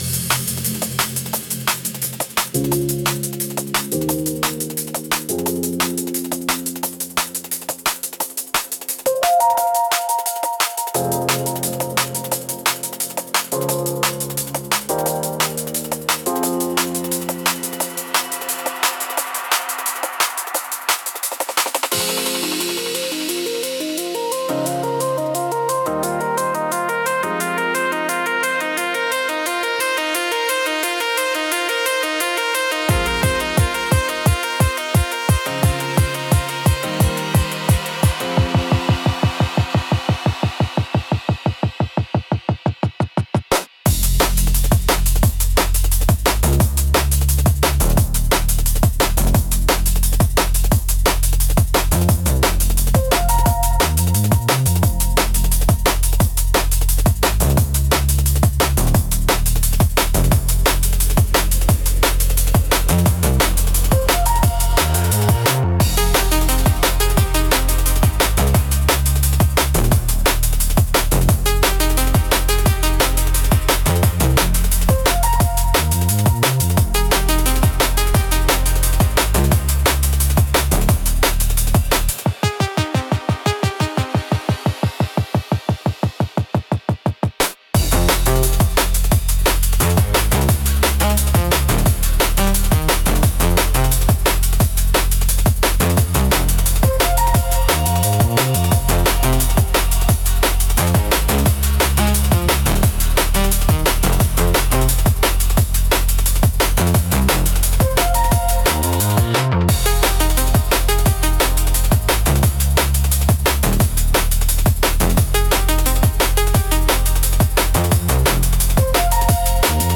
テンポの速さと複雑なリズムで、聴く人の集中力と興奮を引き上げる効果があります。エッジの効いたダイナミックなジャンルです。